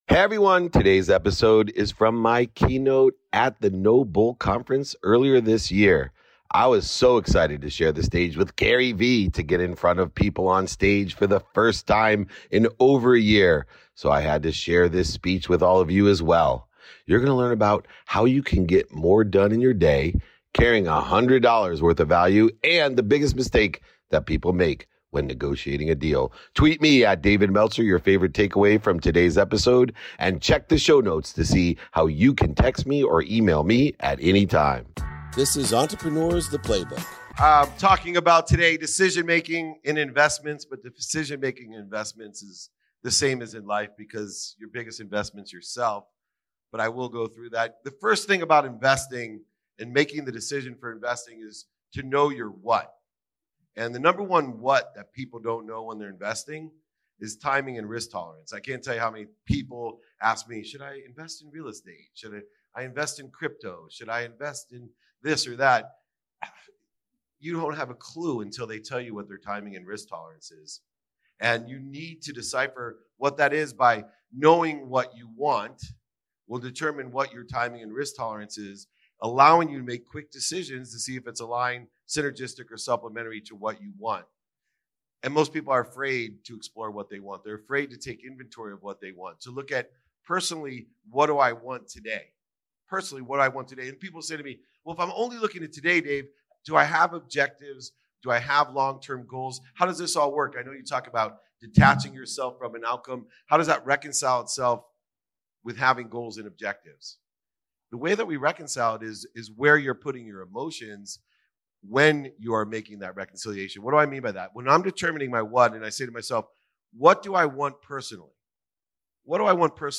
Today's episode is from my Keynote at the NoBullCON. I was so excited to finally be on a stage in front of a live audience for the first time in over a year, so I needed to share this one with all of you.